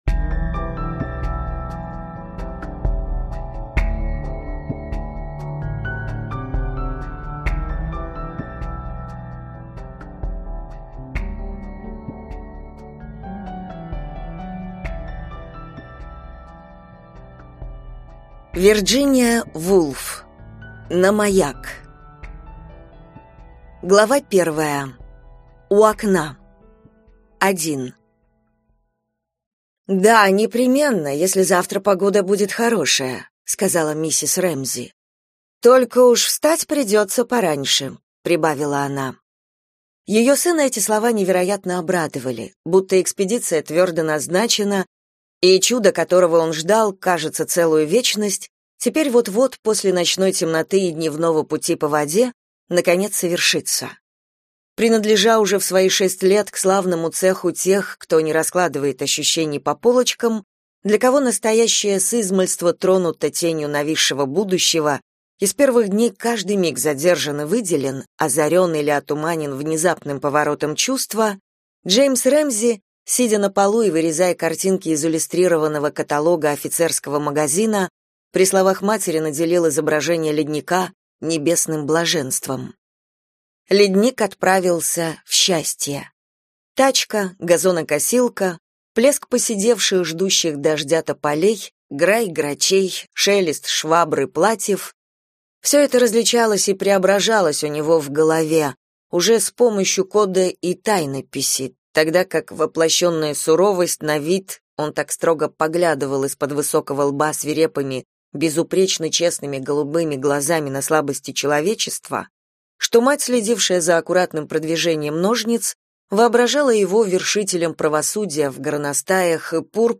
Прослушать фрагмент аудиокниги На маяк Вирджиния Вулф Классика Зарубежная классика Классическая проза Произведений: 5 Скачать бесплатно книгу Скачать в MP3 Вы скачиваете фрагмент книги, предоставленный издательством